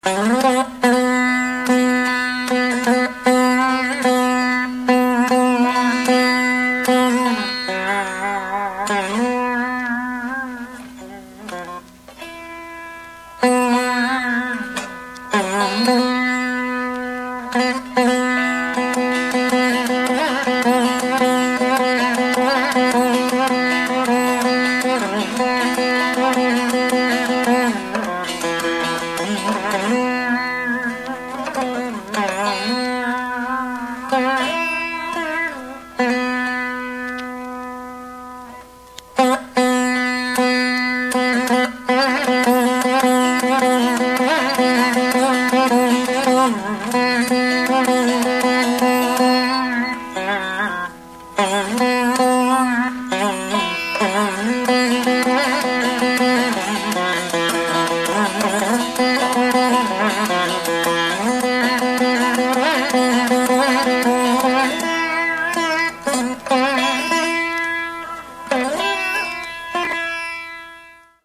vīna